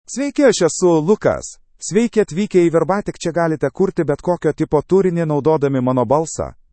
LucasMale Lithuanian AI voice
Lucas is a male AI voice for Lithuanian (Lithuania).
Voice sample
Listen to Lucas's male Lithuanian voice.
Lucas delivers clear pronunciation with authentic Lithuania Lithuanian intonation, making your content sound professionally produced.